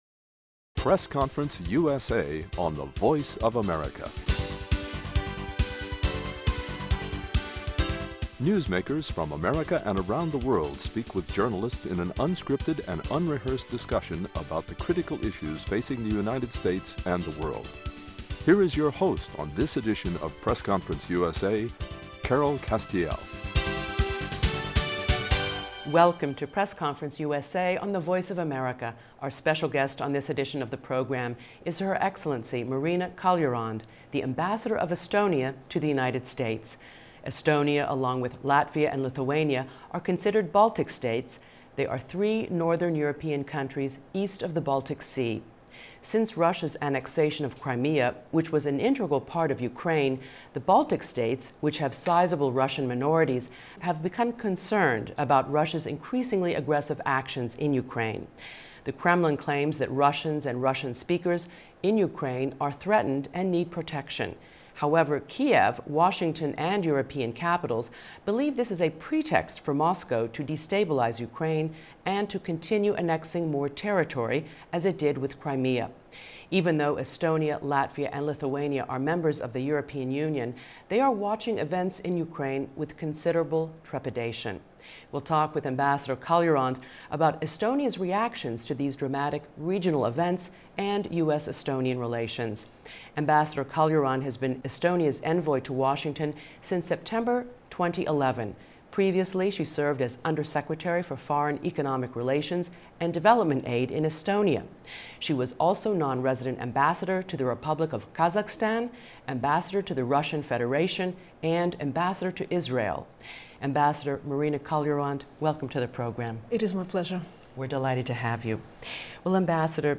H.E. MARINA KALJURAND, AMBASSADOR OF ESTONIA TO THE UNITED STATES On this edition of the program, a wide-ranging conversation with Estonian Ambassador to the U.S., Marina Kaljurand, about Russia's provocative moves in Ukraine – from the annexation of Crimea to its backing of separatists in the East.